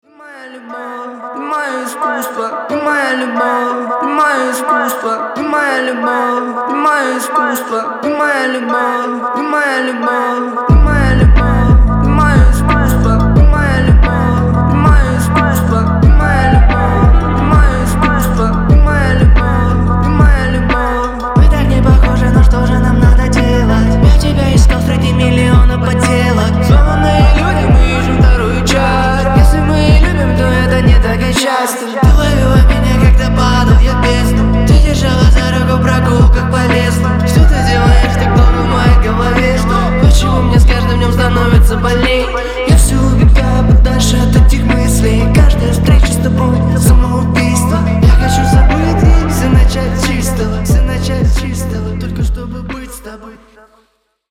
• Качество: 320, Stereo
атмосферные
басы
необычные